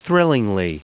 Prononciation du mot thrillingly en anglais (fichier audio)
Prononciation du mot : thrillingly